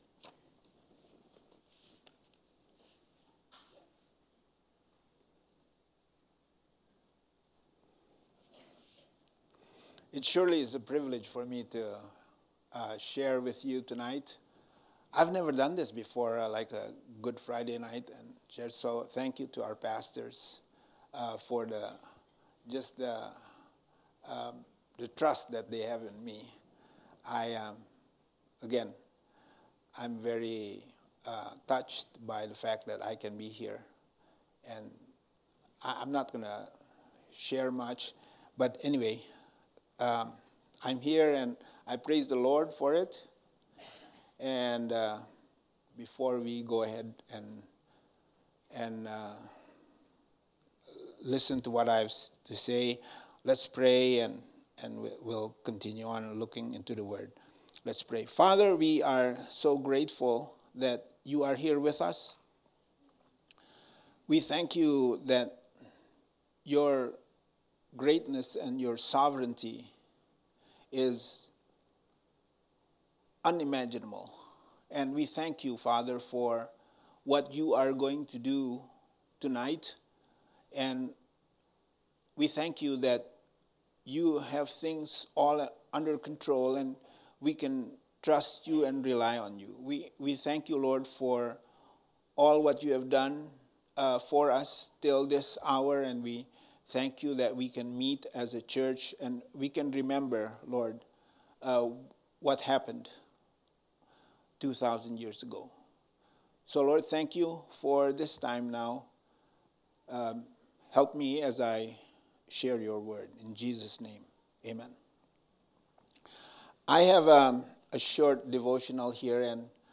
Good Friday Message 2017 – GBC Elder